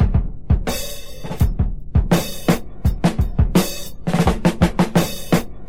• 84 Bpm High Quality Drum Beat E Key.wav
Free drum loop sample - kick tuned to the E note. Loudest frequency: 1124Hz
84-bpm-high-quality-drum-beat-e-key-4Tv.wav